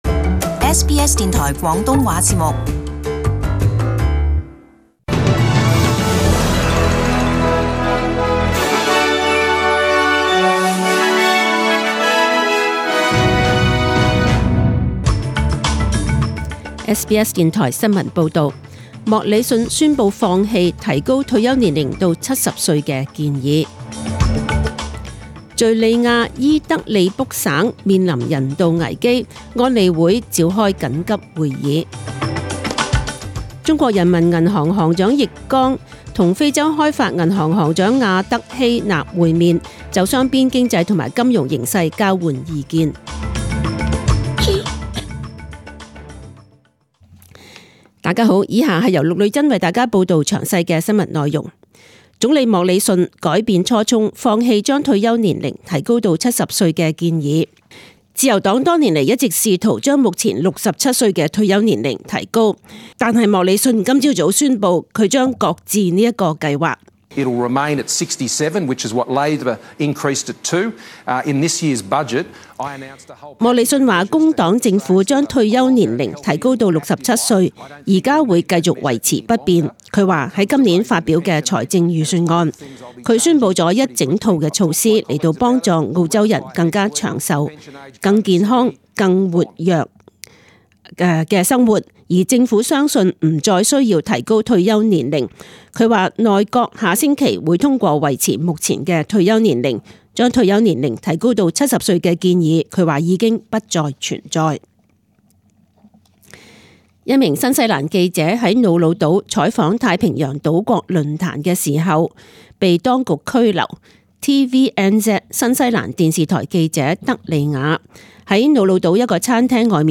SBS中文新闻 （九月五日）